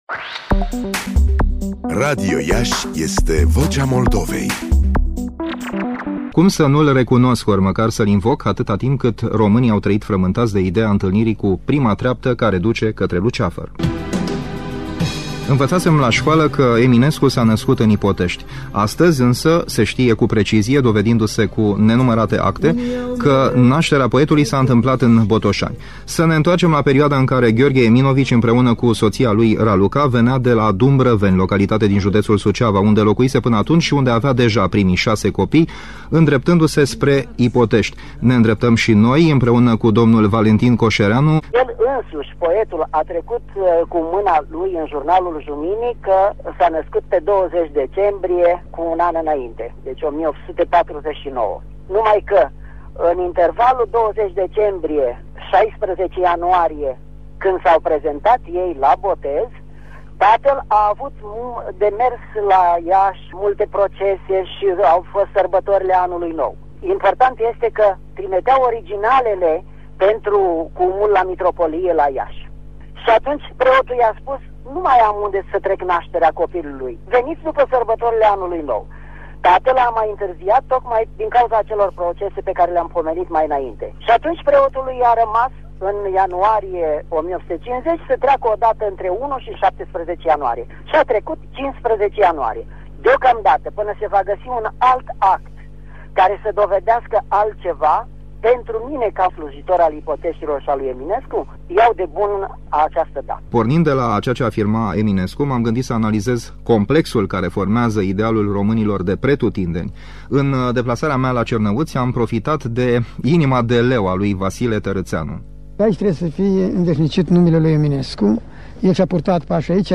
Audio Feature